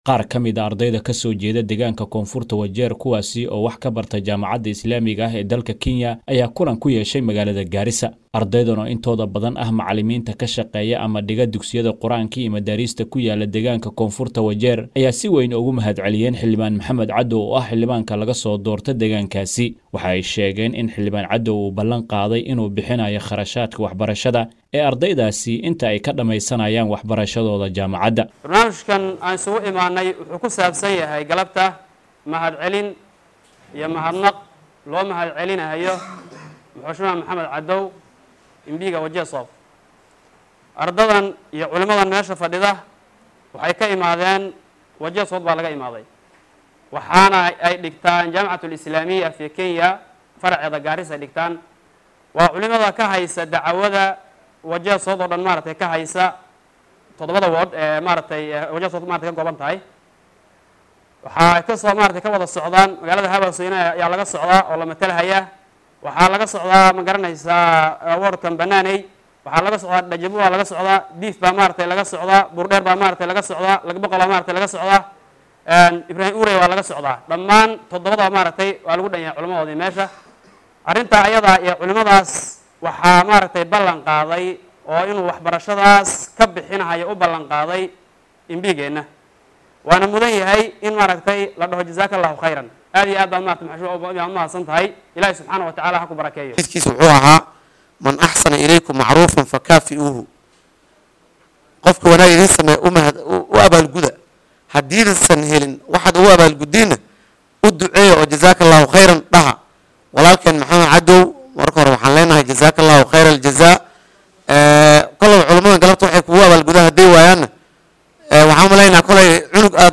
Waxaa magaalada Gaarisa lagu qabtay kulan looga hadlay xanuunka qalalka. Qaar ka mid ah dadka deegaanka, guddoomiye xaafadeedyada iyo ka soo qayb galayaasha kale ee kulanka oo warbaahinta la hadlay ayaa waxaa hadaladooda ka mid ahaa.